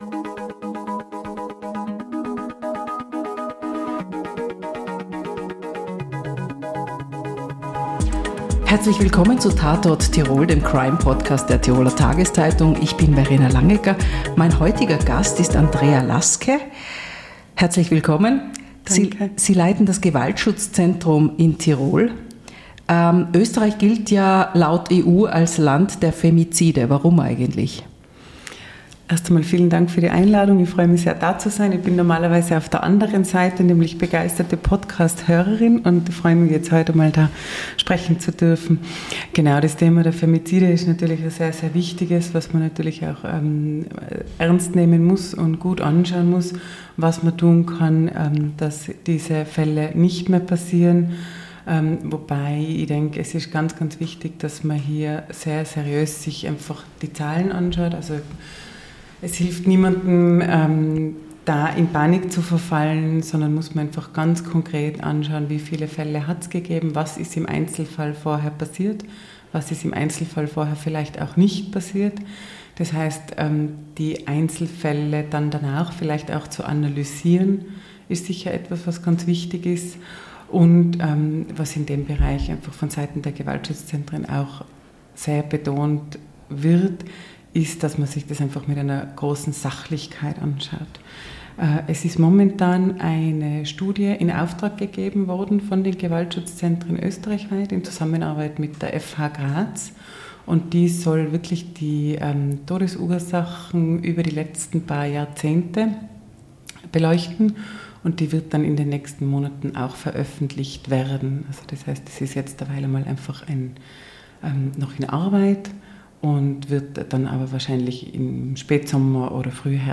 Beschreibung vor 8 Monaten „Tatort Tirol“ ist der Crime-Podcast der Tiroler Tageszeitung. Auch in der Zweiten Staffel bitten wir Menschen zum Gespräch, die beruflich mit Verbrechen zu tun haben.